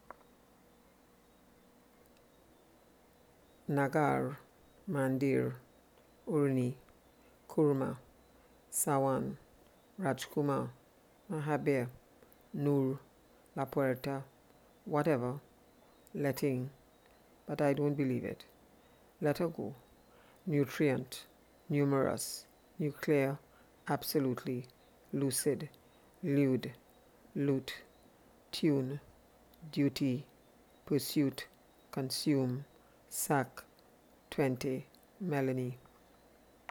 A Description of Trinidadian English Pronunciation.